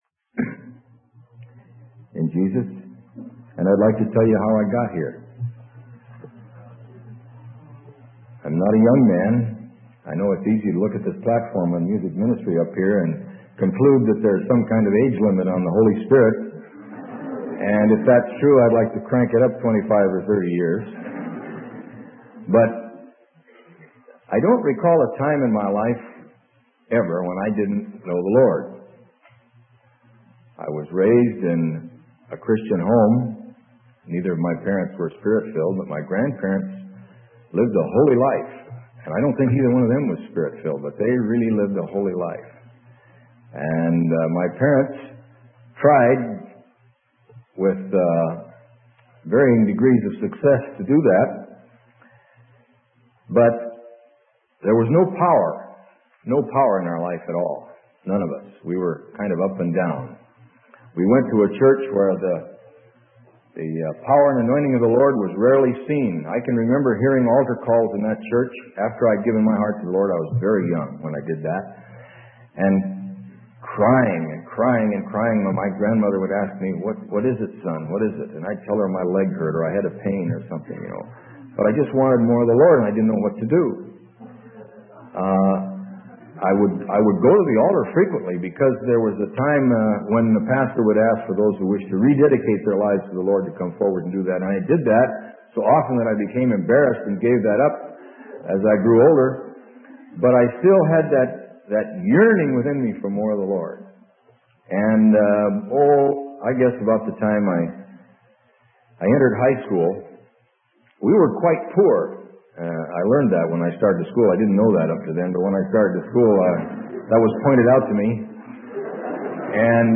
Sermon: A Testimony - Freely Given Online Library